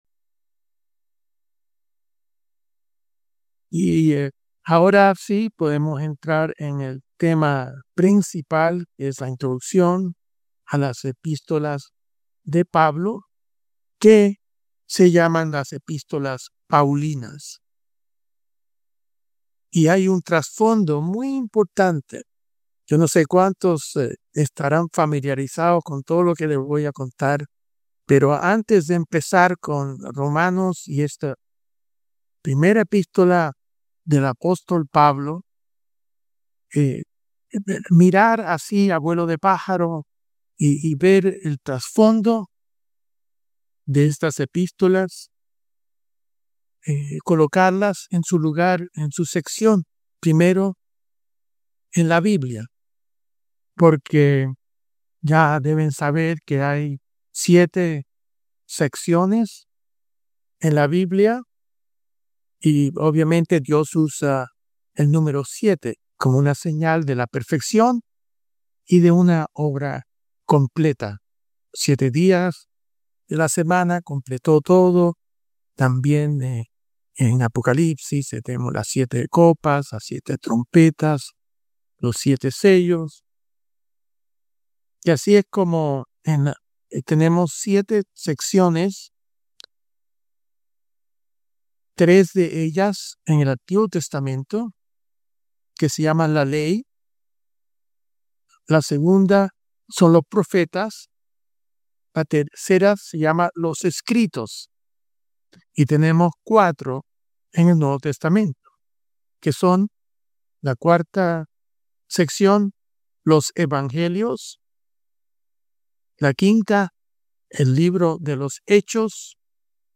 Estudio Bíblico